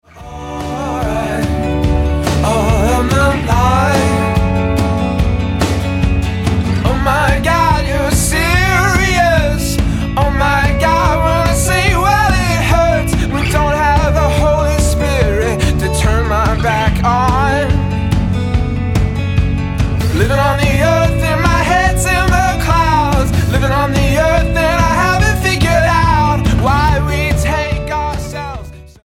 alternative rock
Style: Rock